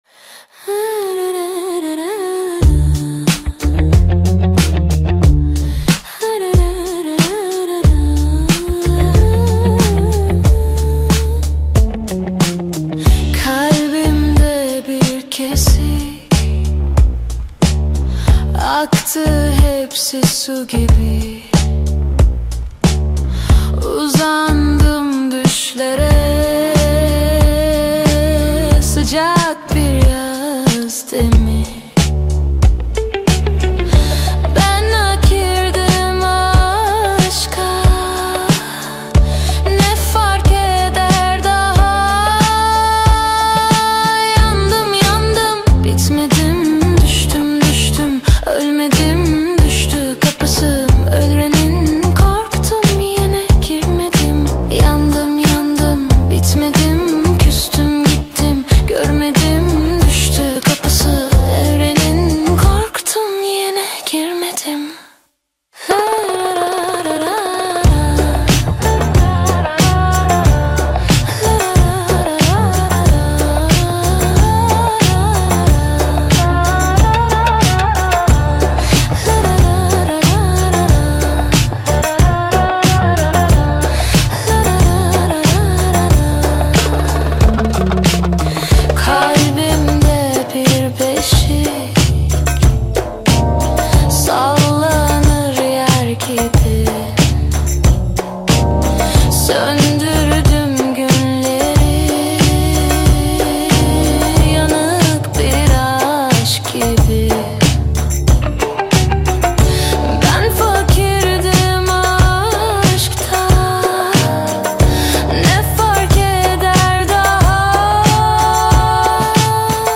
Tür : Funk, Indie-Pop, Pop